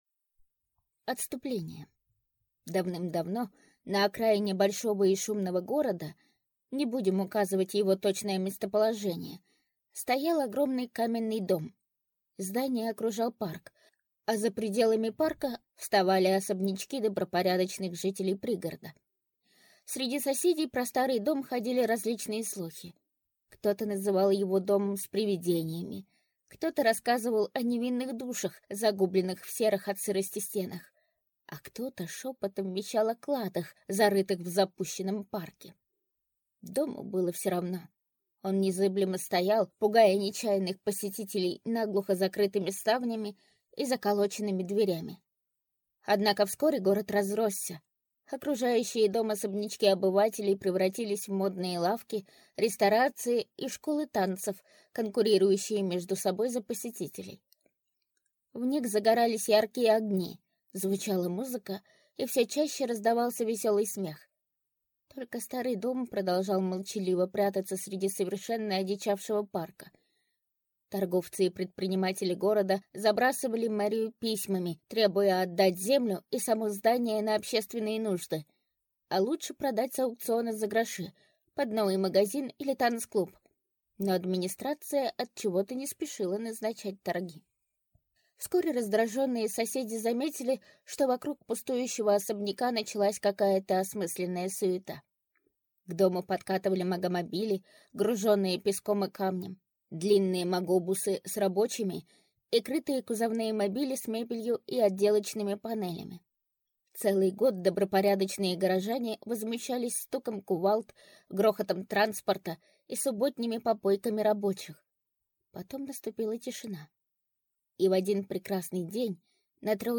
Аудиокнига Клуб «Огненный дракон» | Библиотека аудиокниг